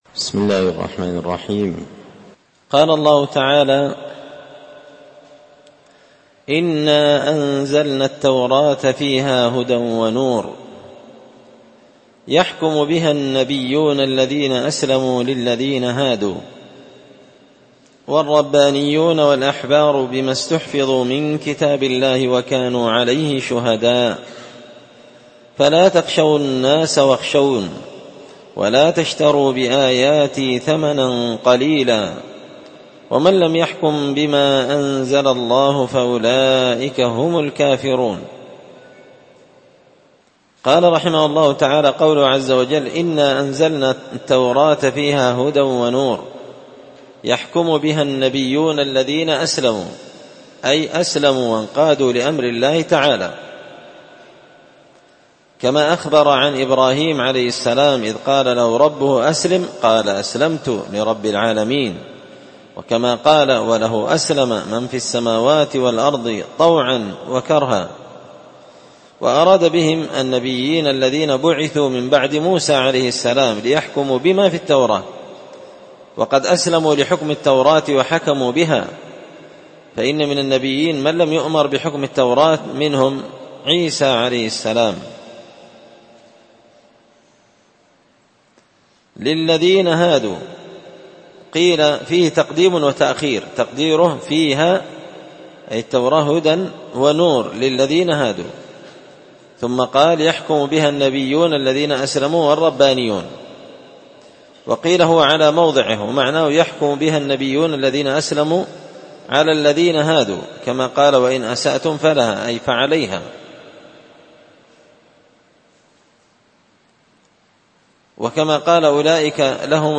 مسجد الفرقان